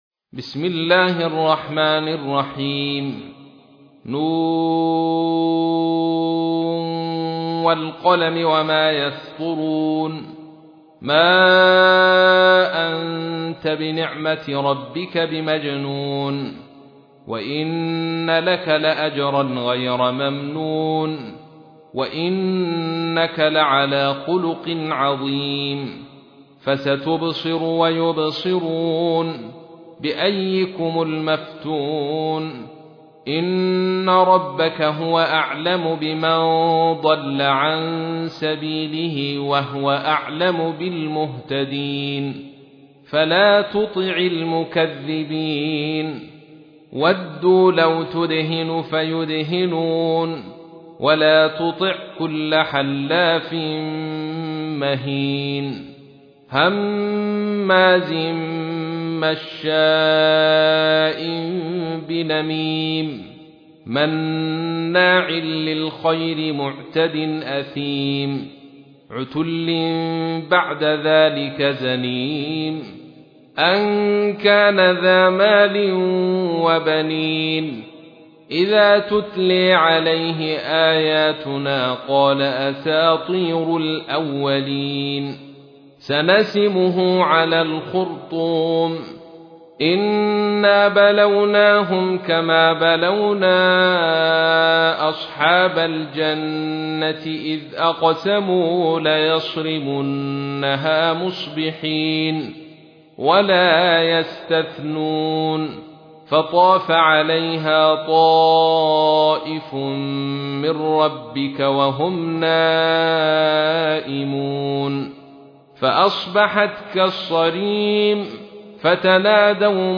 تحميل : 68. سورة القلم / القارئ عبد الرشيد صوفي / القرآن الكريم / موقع يا حسين